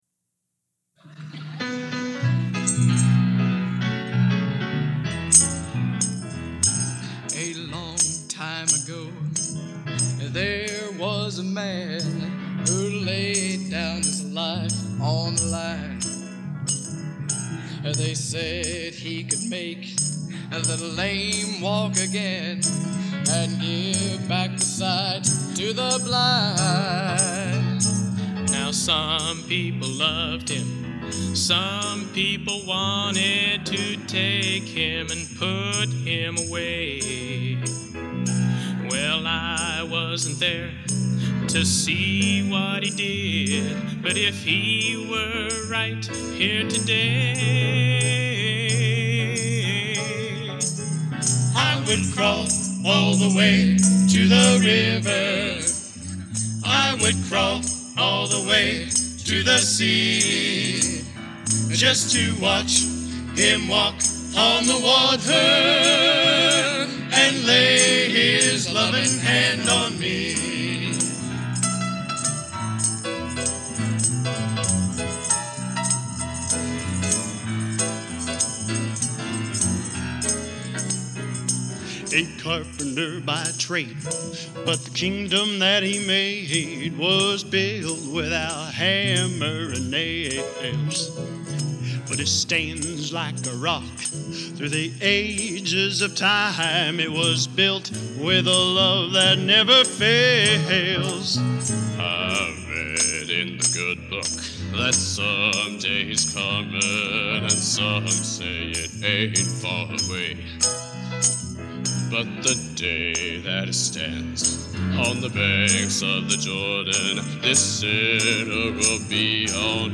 Genre: Gospel | Type: